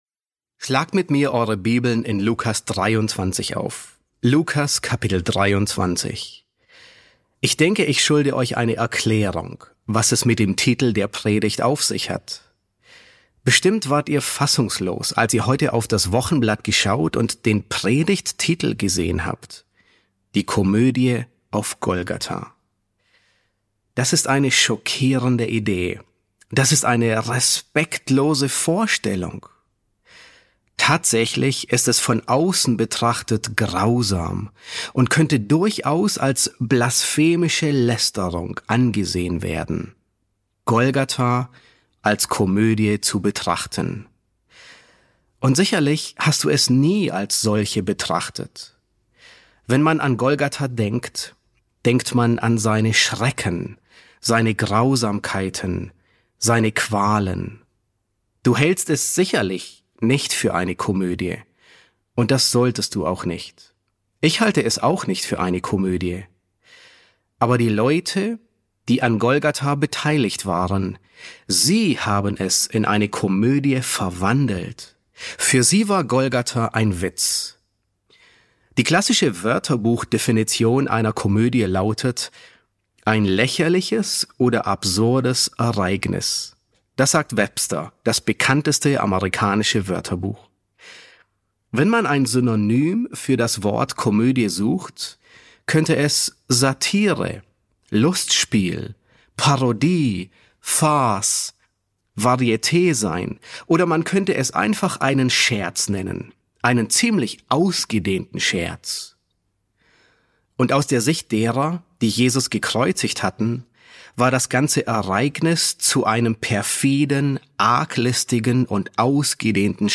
E1 S1 | Der gekreuzigte König: Die Komödie auf Golgatha ~ John MacArthur Predigten auf Deutsch Podcast